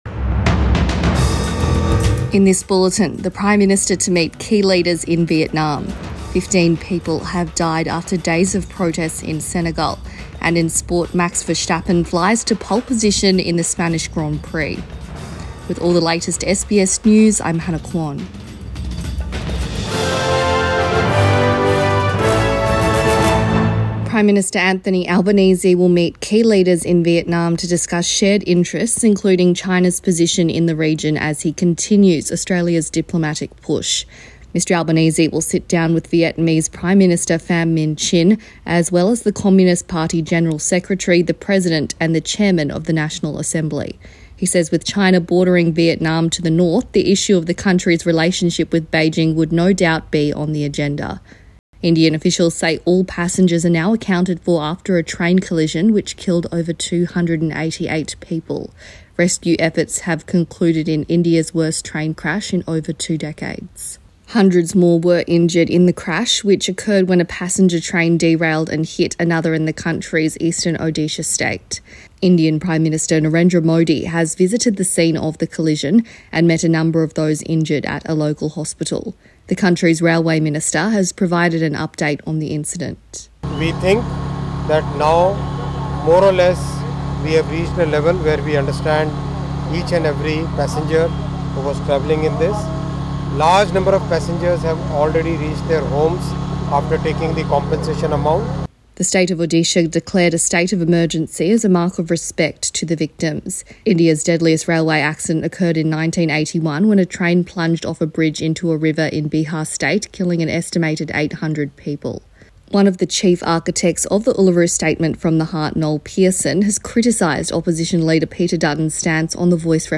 Midday News Bulletin 4 June 2023